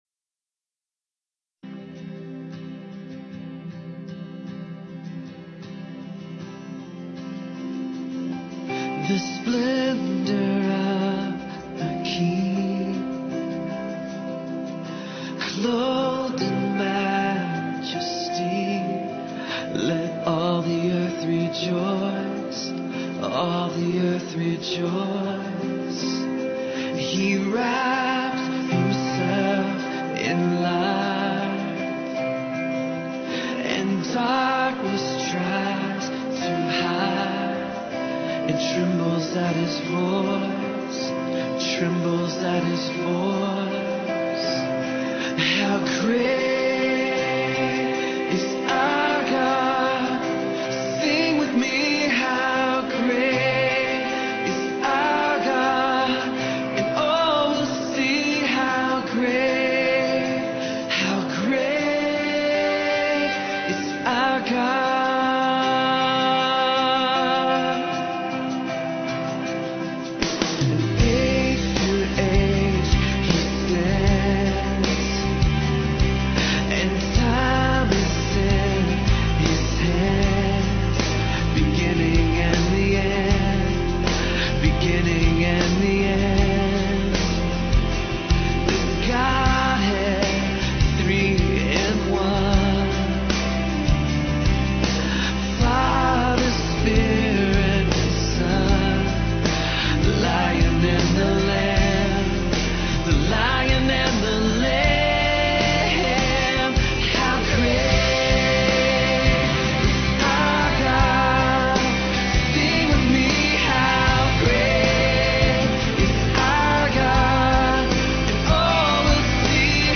Hymn : How Great is Our God